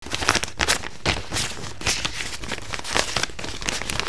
Luckily, I was equipped with a good microphone and a digital tape recorder (it's amazing what you can find in a simple space such as an office).
Clickhere to listen to the first found sound again; this is a fire alarm bell that was hanging on the wall.
In this example, I used a coin to strike the metal.